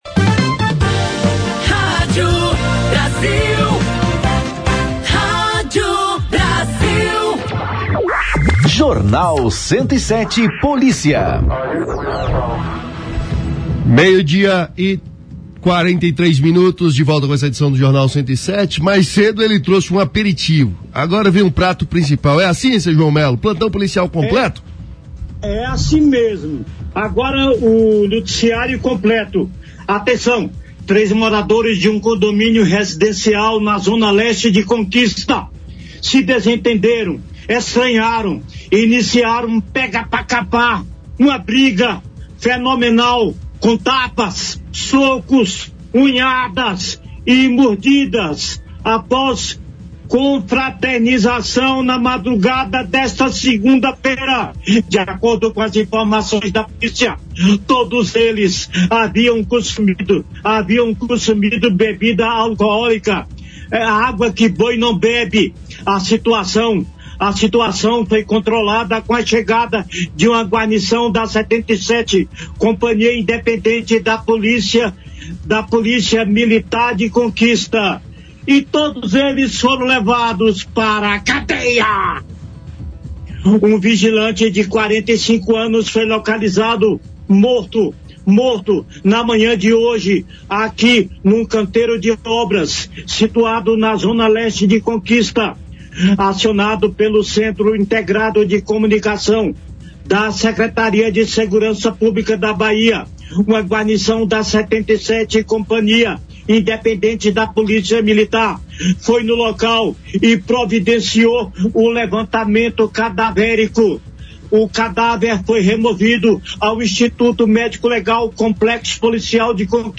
O ministro da Casa Civil, Rui Costa, afirmou que é falsa a informação divulgada pela prefeita Aa Ana Sheila Lemos Andrade de que não houve retorno do Governo Federal sobre recursos para obras de drenagem em Vitória da Conquista. A cronologia da inscrição das obras no Programa de Aceleração ao Crescimento (PAC) e as etapas seguintes foram detalhadas pelo ministro durante entrevista ao UP Notícias, na Rádio UP, nesta terça-feira (10).